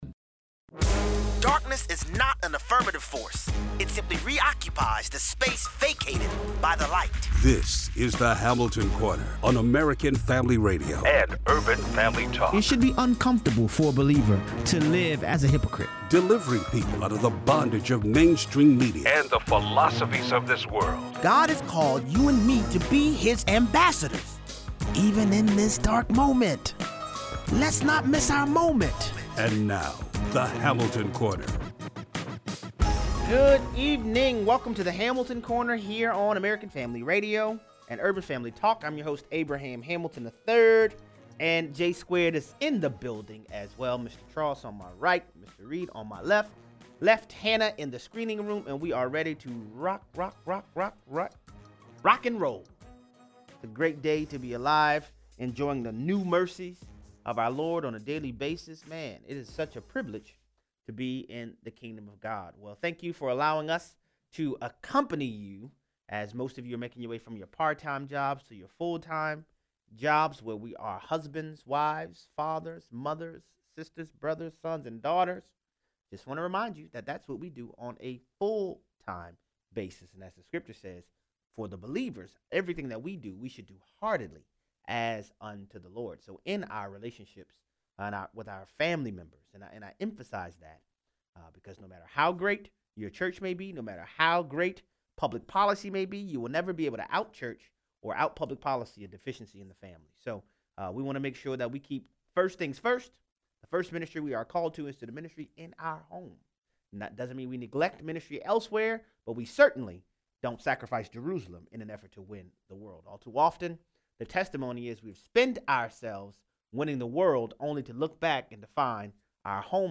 Callers weigh in.